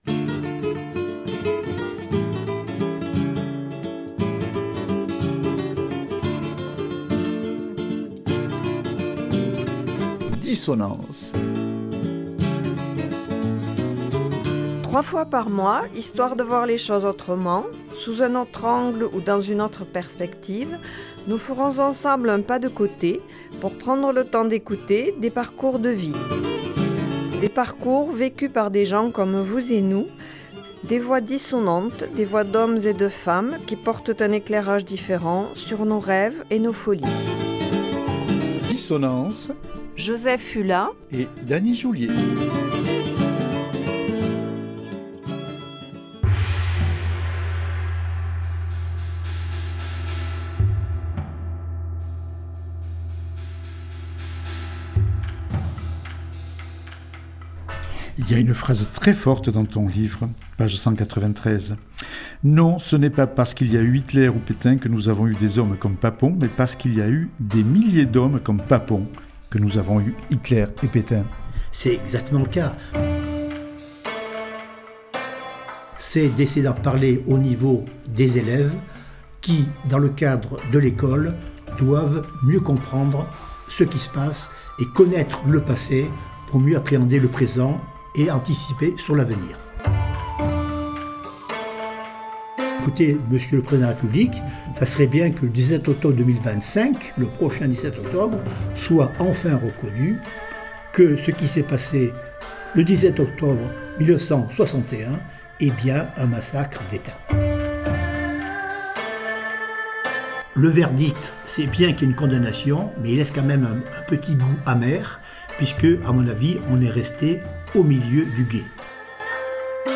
Document INA: JT d’Antenne 2 du 16 octobre 1997.